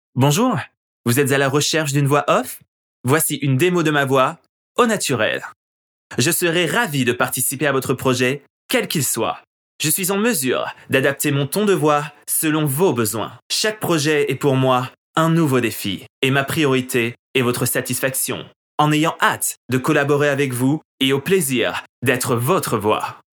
NEUTRE